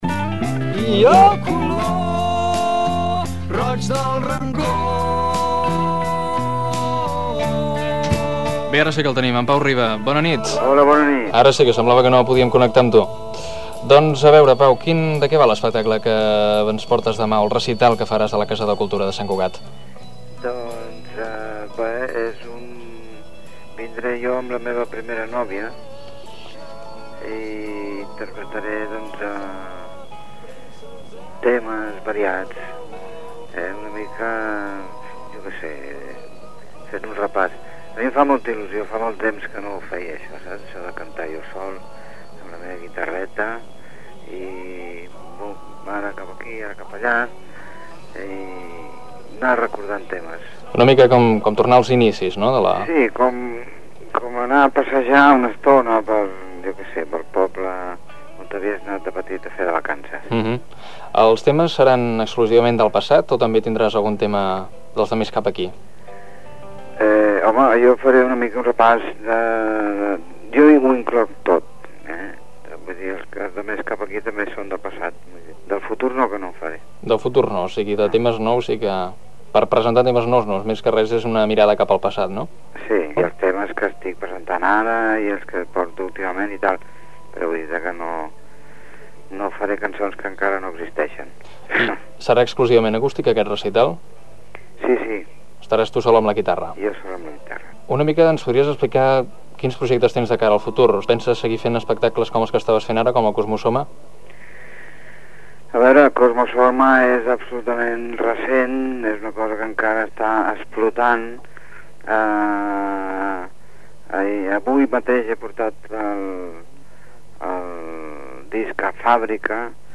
4c1f4b65e73cd632c9011ea2d86eb05c9a0f995e.mp3 Títol Ràdio Sant Cugat Emissora Ràdio Sant Cugat Titularitat Pública municipal Nom programa Cau de llops Descripció Entrevista al músic Pau Riba sobre l'espectacle que farà a Sant Cugat i les seves opinions sobre la creació musical.